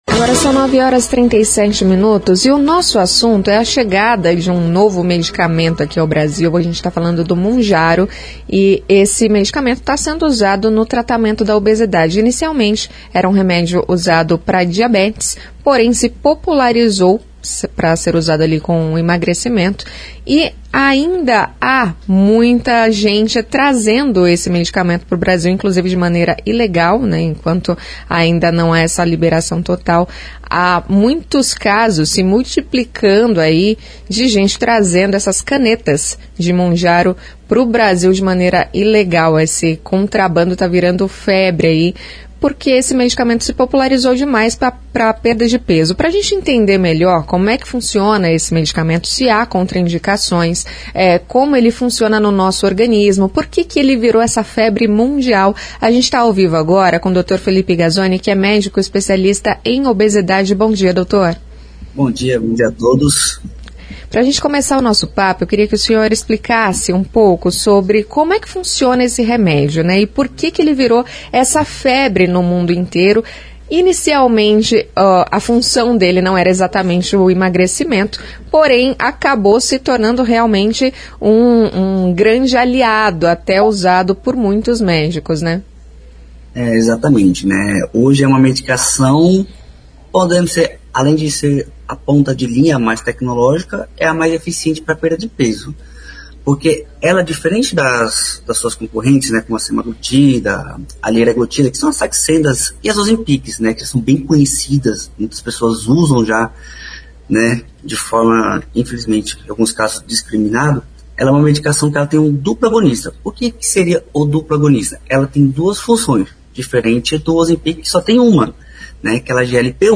Especialista em obesidade comenta chegada do Mounjaro ao Brasil